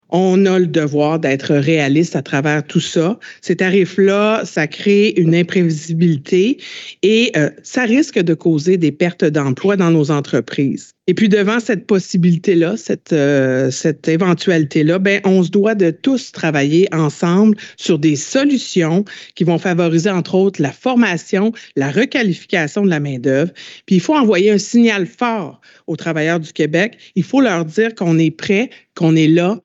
Voici un extrait de son mot d’ouverture, auquel les médias ont eu accès :
Extrait – Kateri Champagne-Jourdain – Ouverture du Forum sur l’Emploi
Extrait-Kateri-forum-ouverture-.mp3